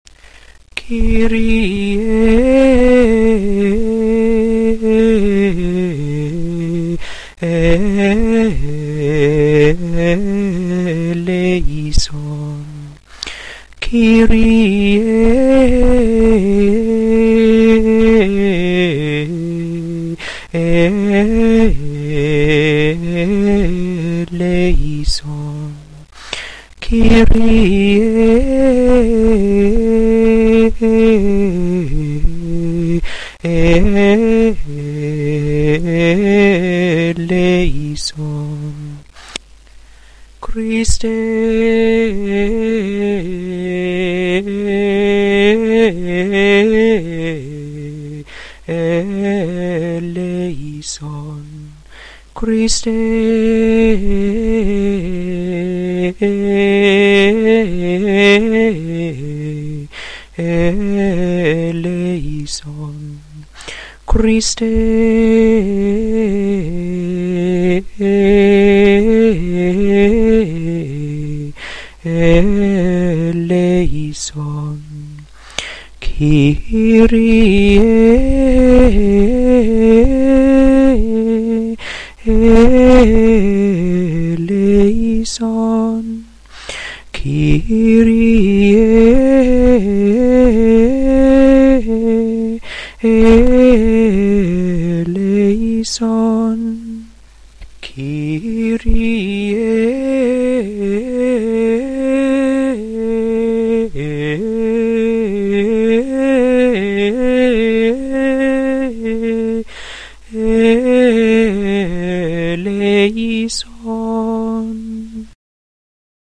2. Gregorian Chant